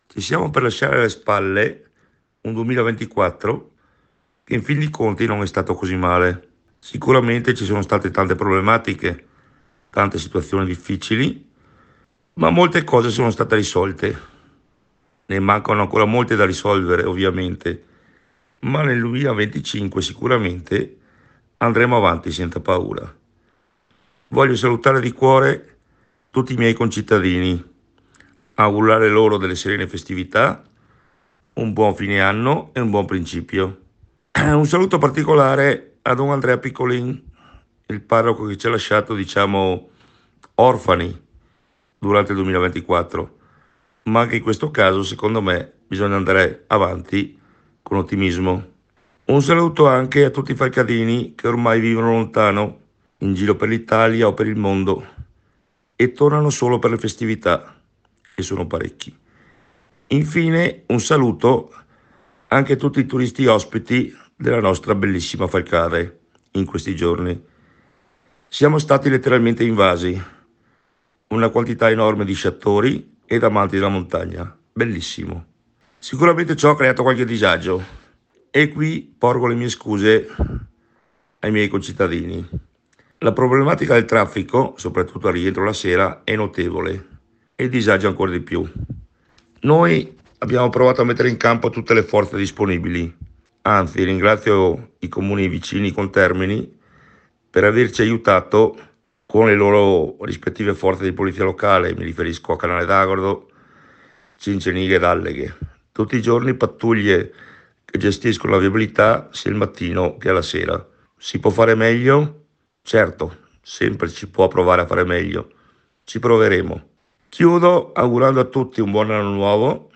IL SINDACO DI FALCADE MAURO SALVATERRA